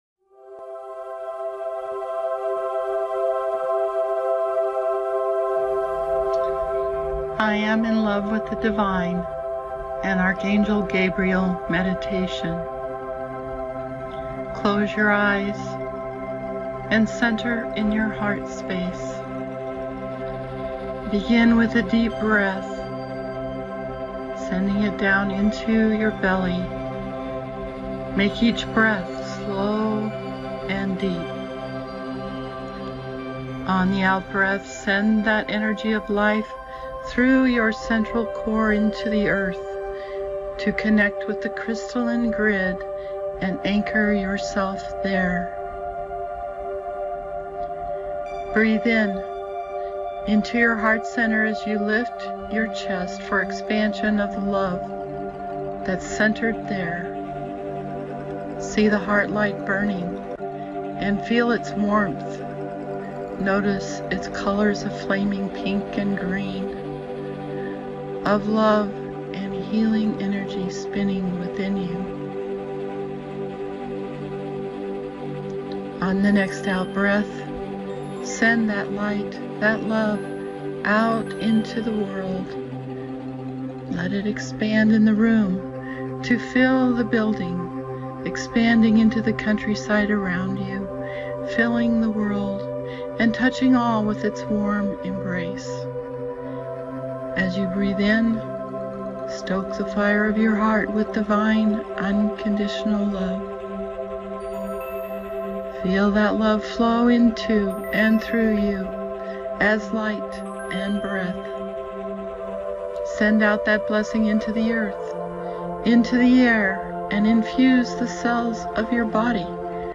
Read more of I AM in Love with the Divine, or scroll down to enjoy the audio meditation from my heart to yours: A Holiday Blessing from Archangel Gabriel.
Music: Ascent to Heaven by Thaddeus.